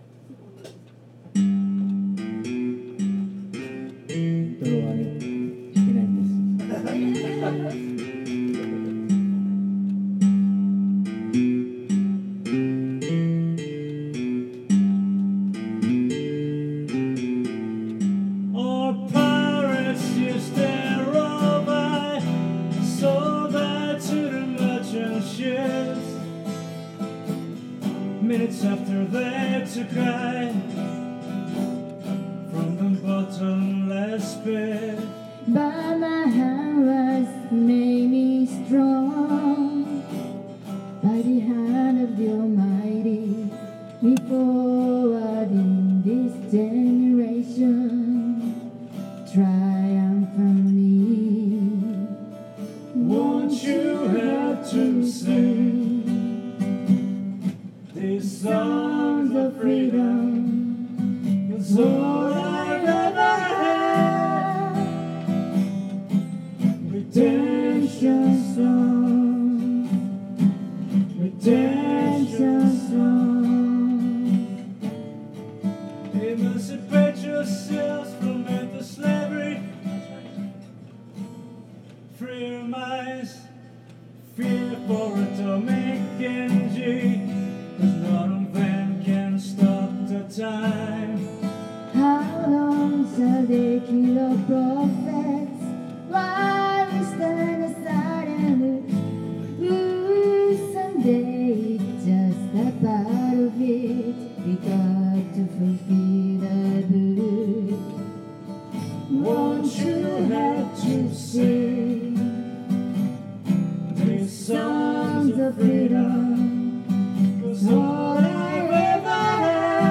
Duet & Chorus Night Vol. 12 TURN TABLE